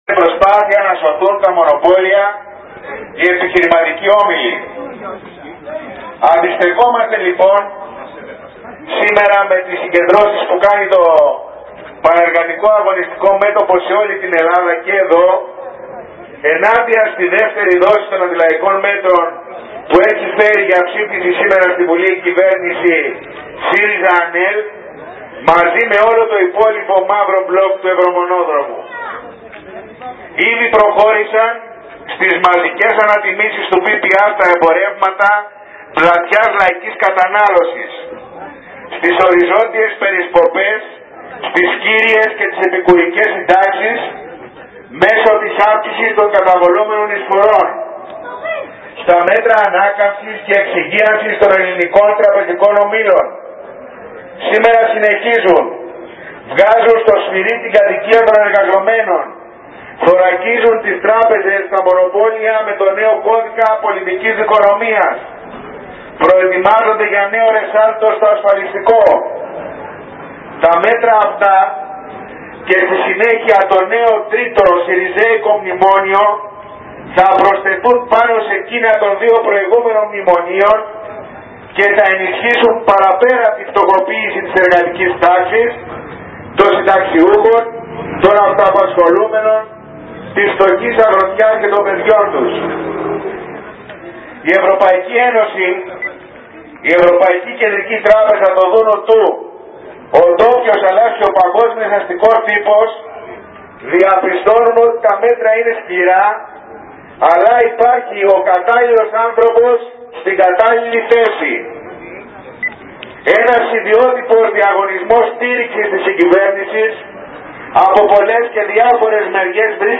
Αγωνιστική απάντηση στα βάρβαρα αντιλαϊκά μέτρα έδωσε το ΠΑΜΕ με συγκέντρωση και πορεία από το Εργατικό Κέντρο.